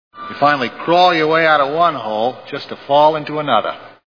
Minority Report Movie Sound Bites